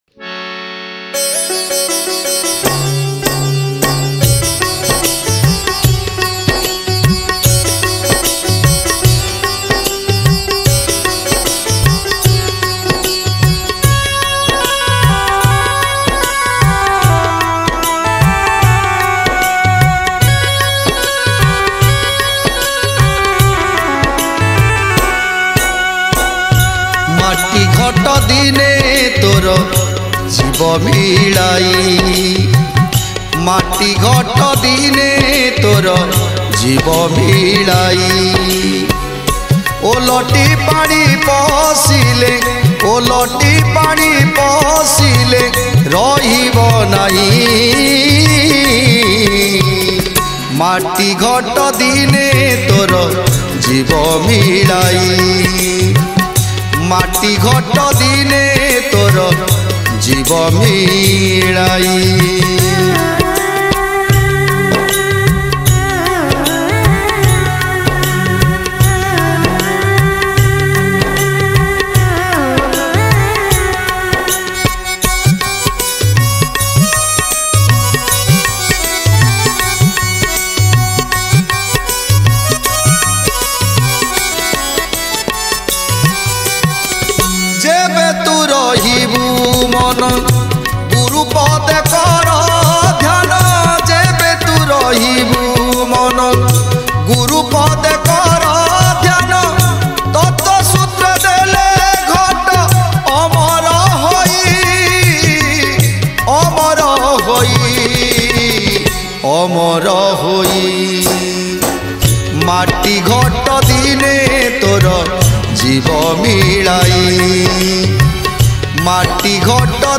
Sri Sri Jagannath Stuti